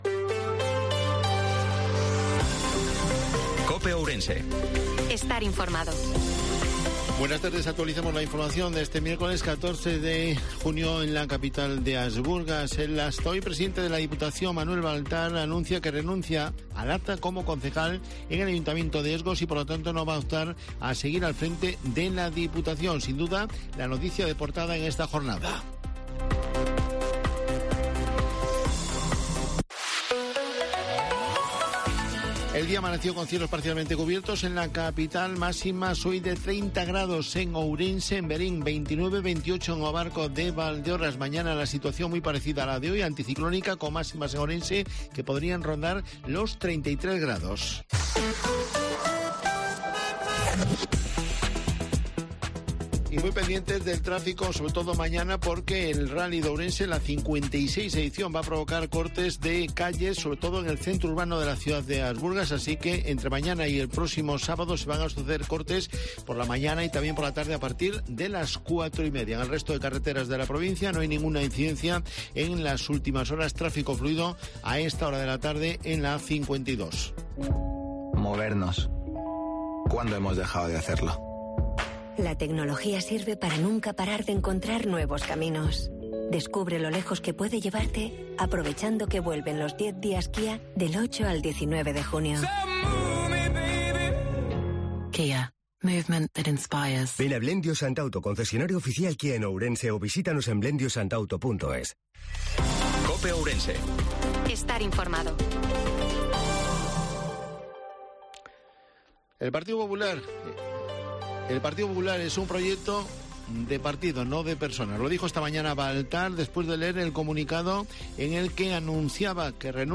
INFORMATIVO MEDIODIA COPE OURENSE-14/06/2023